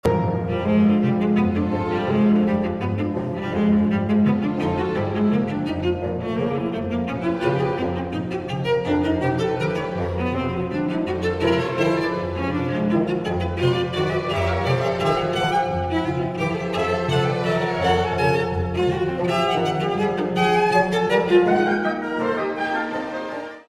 Moderato – (0:24)